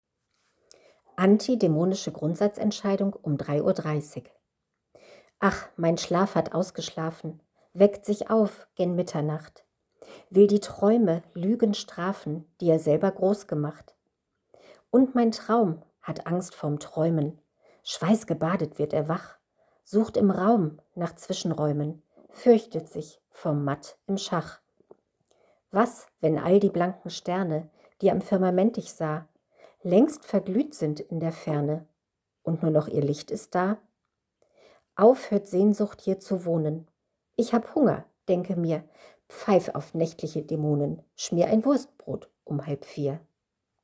🎤 Audio Lesung